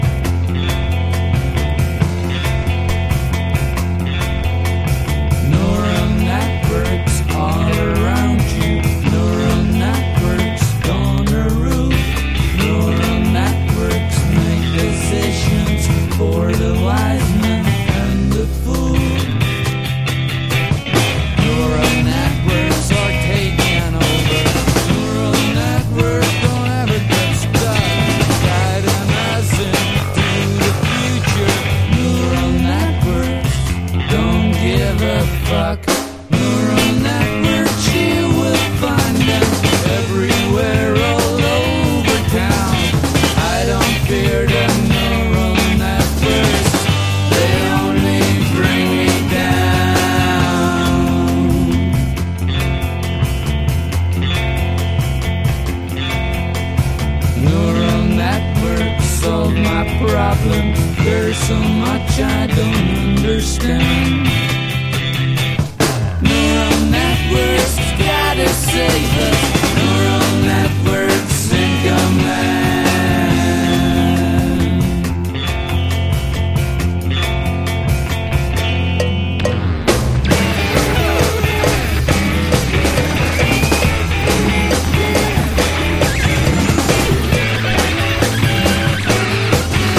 フォーク/ブルース～ソウルを滲ませながらモダンで粋なサウンドに昇華した正にネオモッズな音。